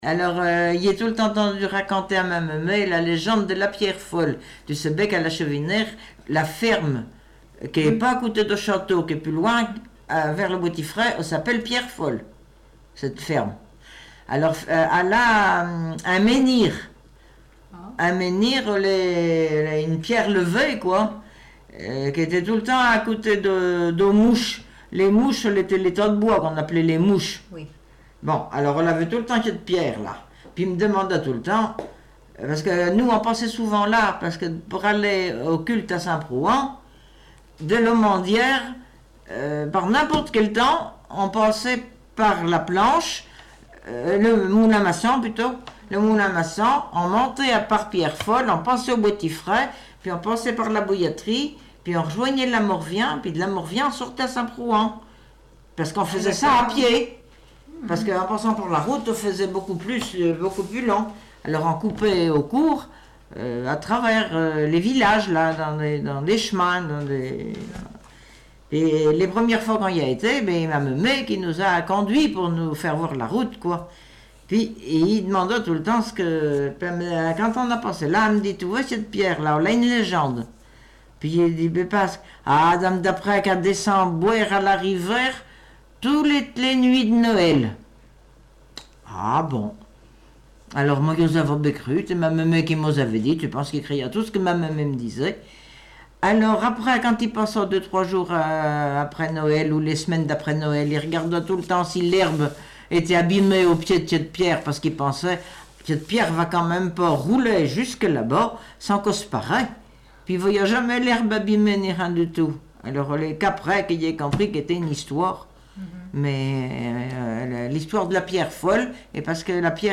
Genre légende
Catégorie Récit